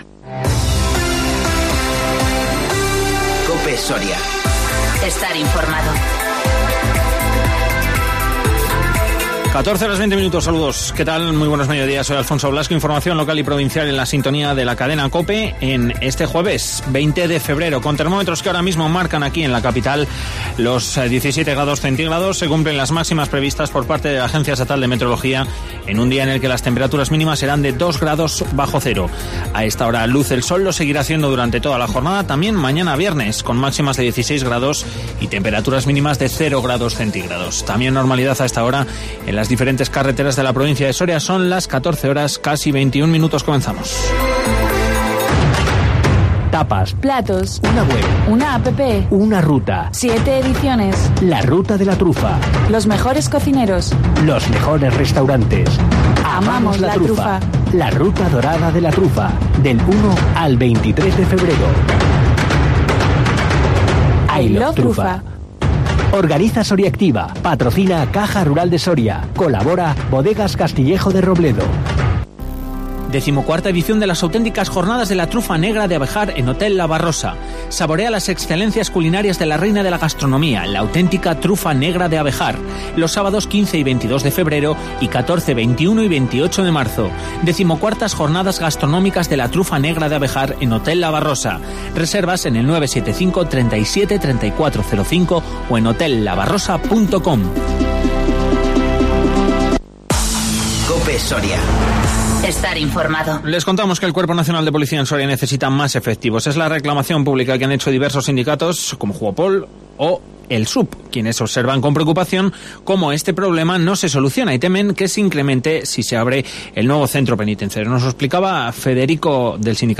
Informativo 20-02-20